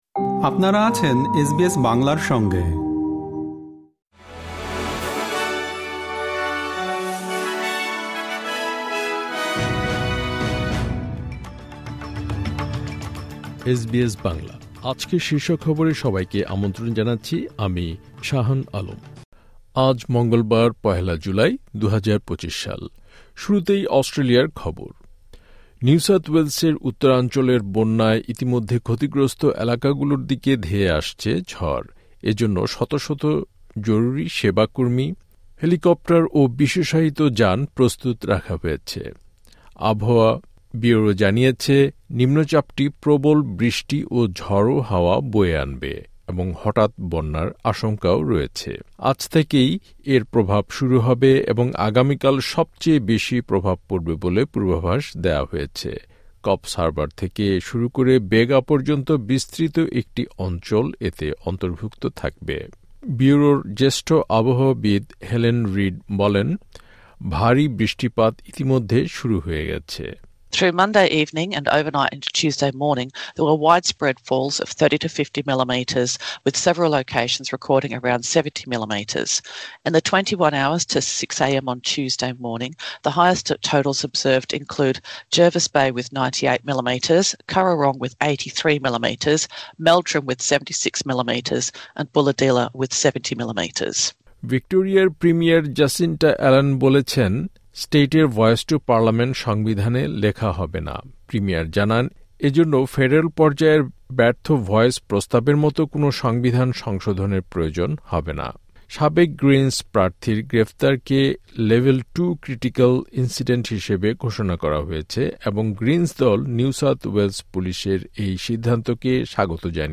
এসবিএস বাংলা শীর্ষ খবর: ১ জুলাই, ২০২৫